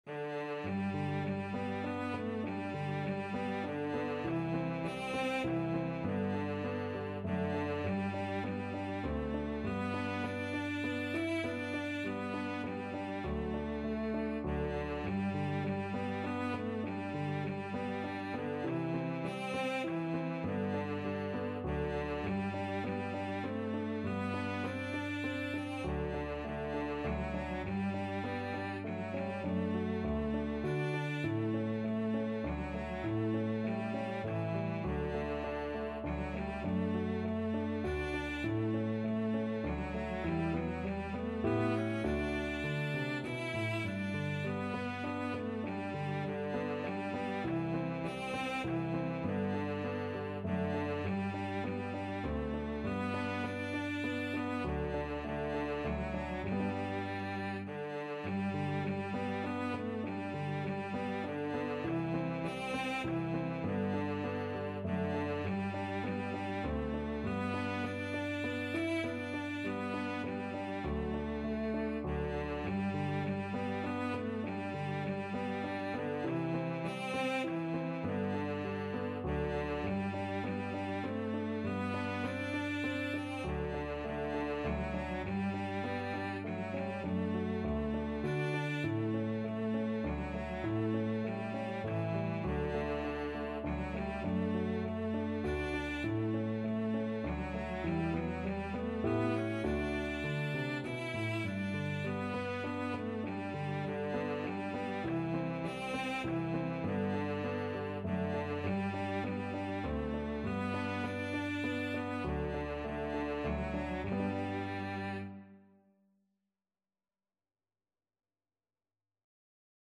Classical Trad. Flow Gently Sweet Afton Cello version
Traditional Music of unknown author.
G major (Sounding Pitch) (View more G major Music for Cello )
Moderato
3/4 (View more 3/4 Music)
Cello  (View more Easy Cello Music)
Classical (View more Classical Cello Music)